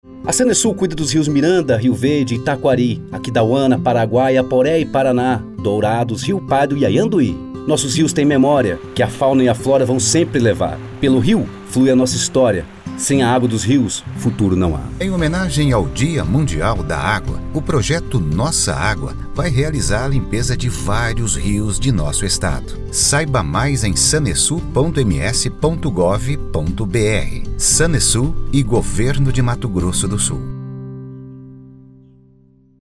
SPOT-SANESUL-RIOS-30-SEG.mp3